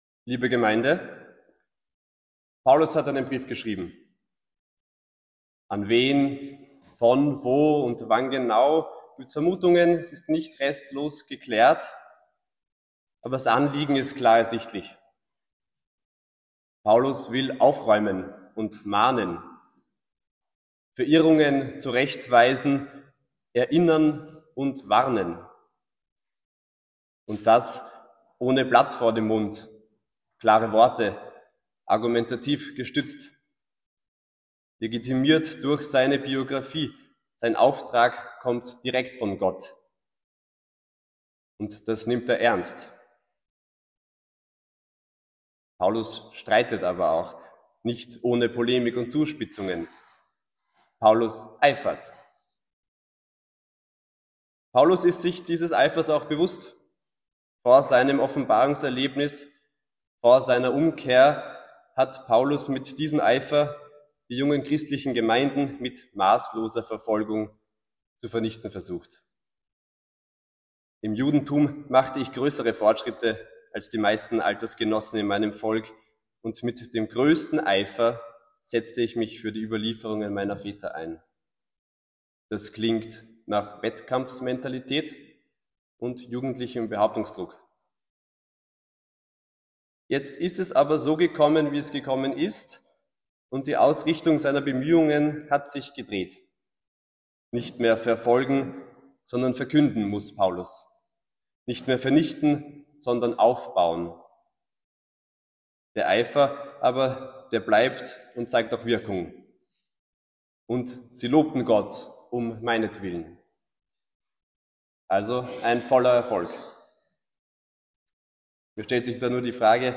Worte zur Schrift: